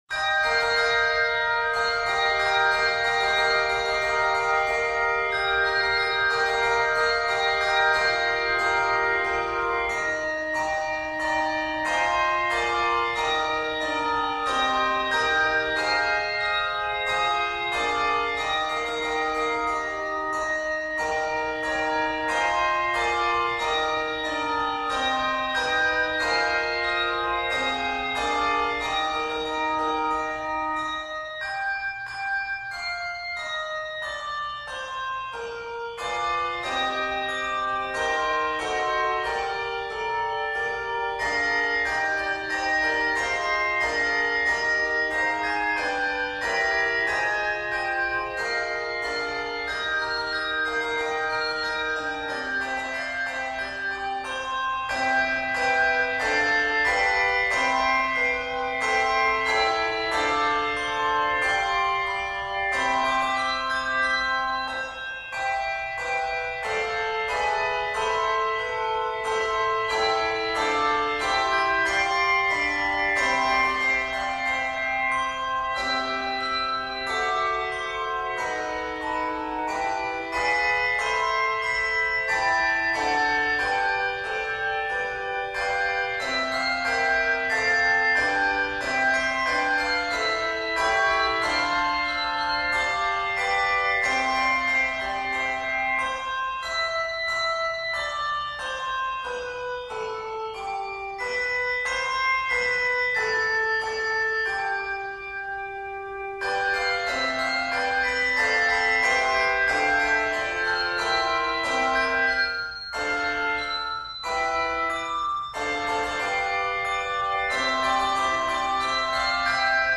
Octaves: 2-3